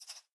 sounds / mob / rabbit / idle1.ogg